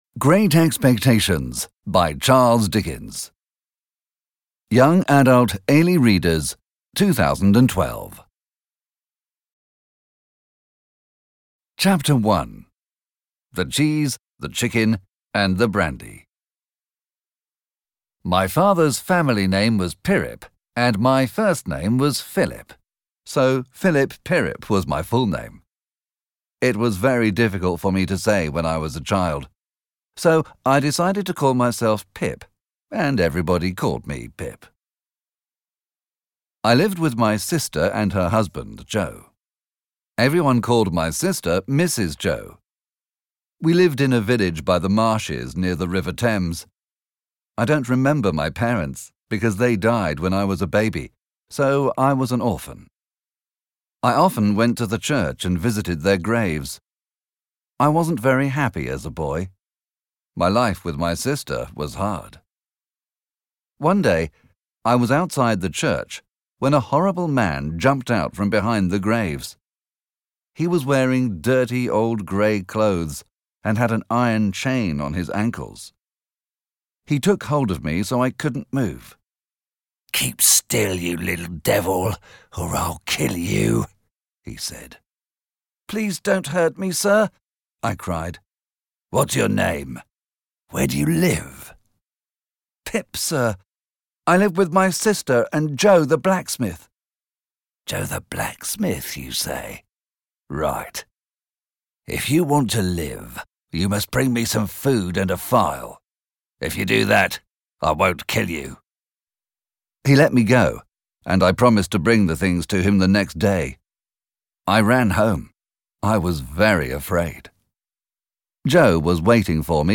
Obtížnost poslechu odpovídá jazykové úrovni A2 podle Společného evropského referenčního rámce, tj. pro studenty angličtiny na úrovni mírně pokročilých začátečníků.
AudioKniha ke stažení, 9 x mp3, délka 1 hod. 17 min., velikost 105,6 MB, česky